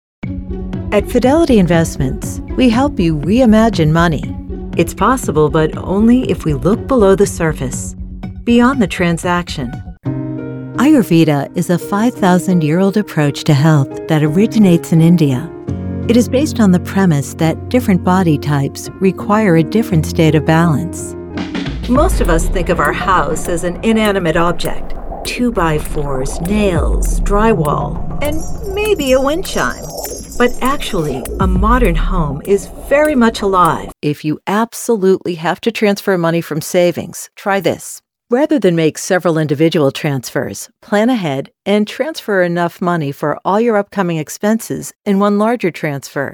Corporate Videos
With a warm grounded (North American English) voice, wry smile and a storyteller’s heart, I want people to feel something real when they listen.
Acoustic Paneled and Sound Treated- 12'x6' recording studio and workstation